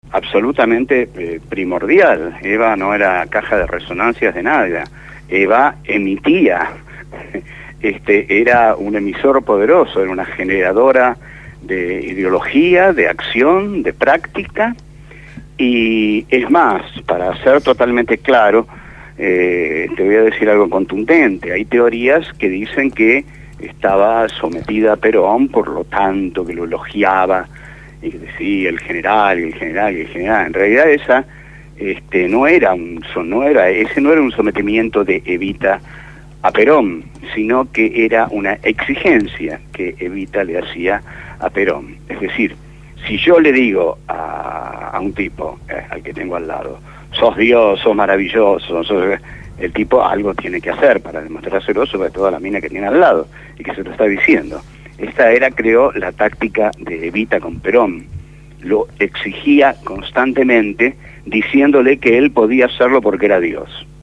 En «Por el Chori y por la Torta» (Domingos, de 22:00 a 23:00) el filósofo José Pablo Feimann fue entrevistado por nuestros compañeros de la Agrupación «Putos Peronistas«.